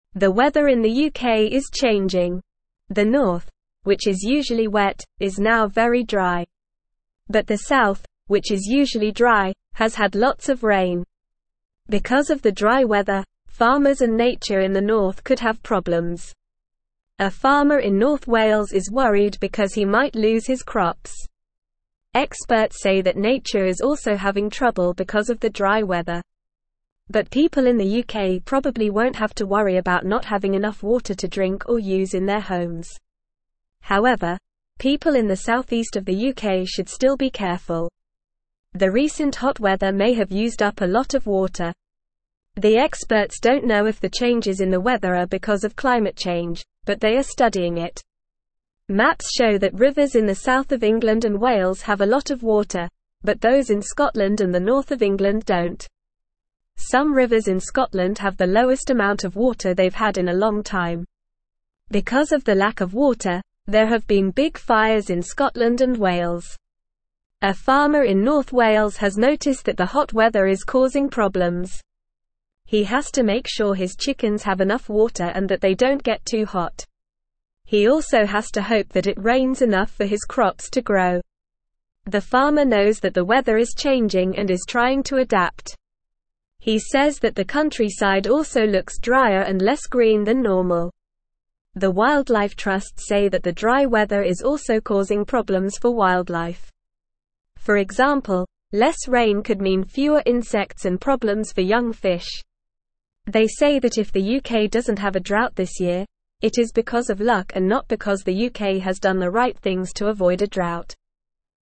Normal
English-Newsroom-Beginner-NORMAL-Reading-UK-Weather-Changes-Worry-Farmers-and-Nature.mp3